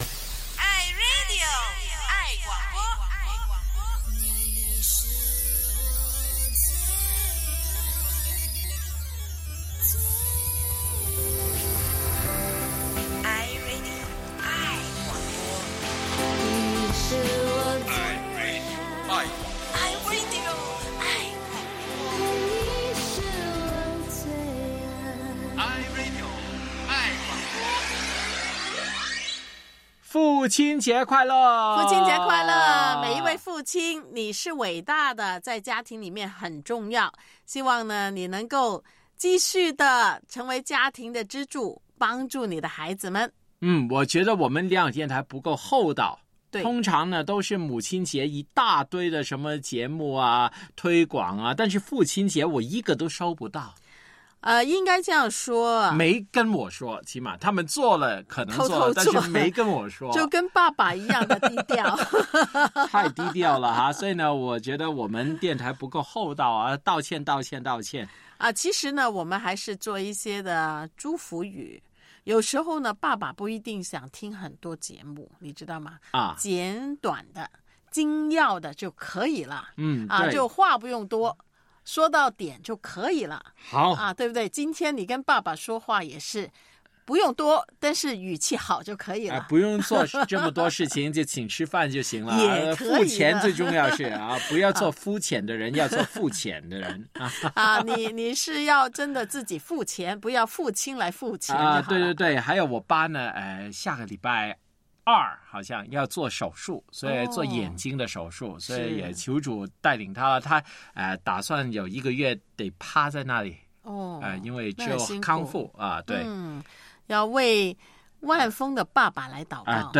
《午的空间》疯狂三宝出动，负责说、唱、逗、笑！全球来拜拜：韩国人拜拜显示了他们是敬畏的民族？